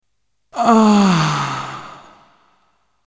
Ambient sound effects
Descargar EFECTO DE SONIDO DE AMBIENTE AHHHHAHHHHHH - Tono móvil